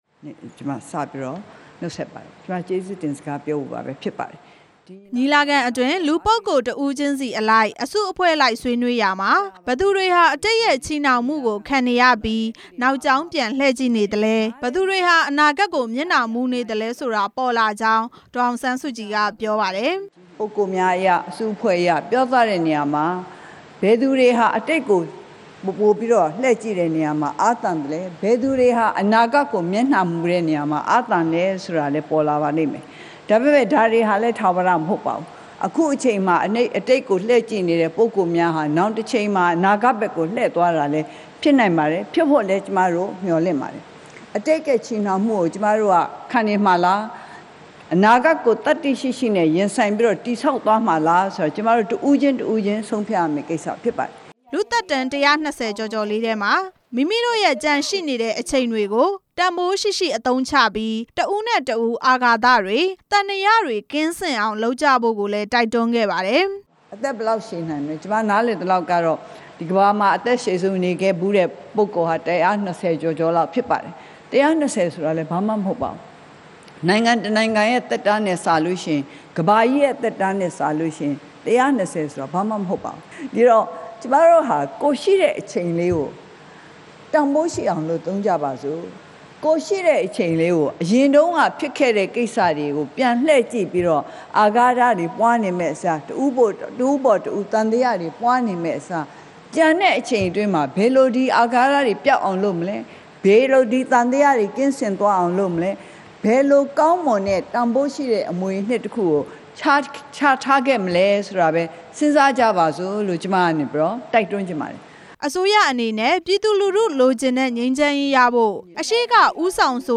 ဒေါ်အောင်ဆန်းစုကြည် ပင်လုံနောက်ဆုံးနေ့ ကျေးဇူးတင်စကား ပြောကြား
၂၁ ရာစု ပင်လုံညီလာခံ နောက်ဆုံနေ့ ဒီနေ့မှာ နိုင်ငံတော်အတိုင်ပင်ခံပုဂ္ဂိုလ် ဒေါ်အောင်ဆန်းစုကြည်က ကျေးဇူးတင်စကား ပြောကြားခဲ့ပါတယ်။